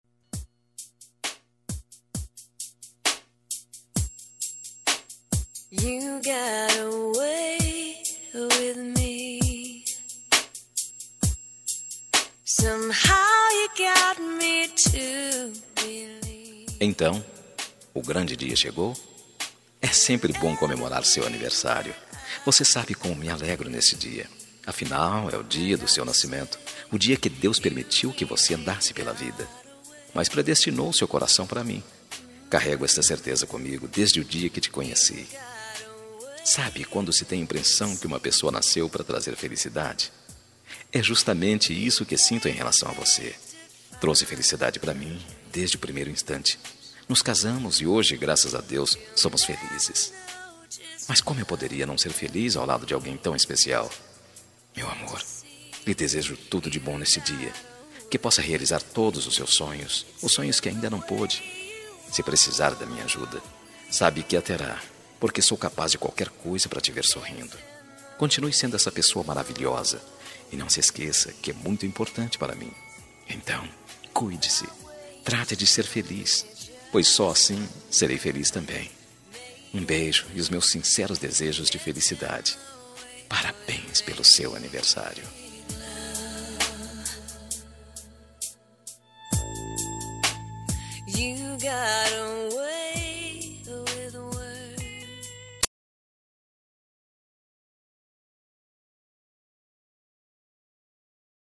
Aniversário Esposa – Voz Masculino – Cód: 350329